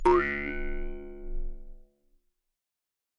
口腔竖琴第1卷 " 口腔竖琴4下限音
描述：口琴（通常被称为“犹太人的竖琴”）调到C＃。 用RØDENT2A录制。
Tag: 竖琴 调整Mouthharp 共振峰 仪器 传统 jewsharp 共振峰 弗利